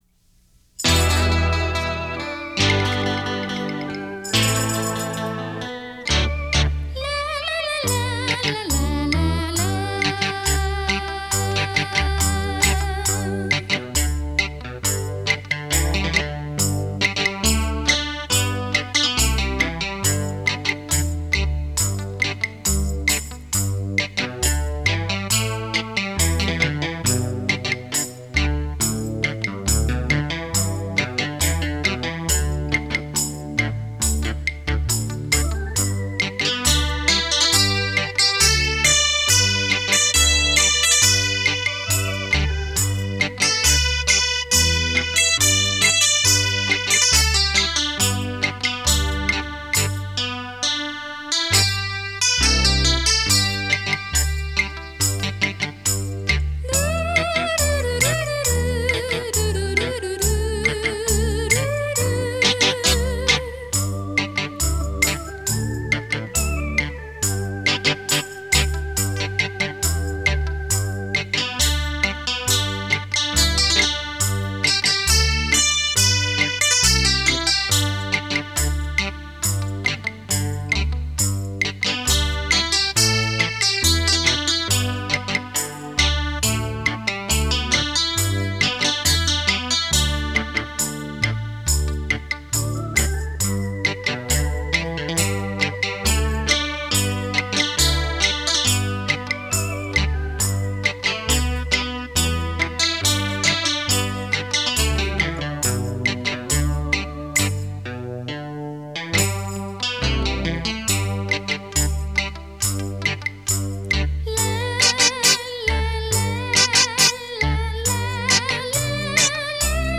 立体效果 环绕身历声
不同风格、不同演奏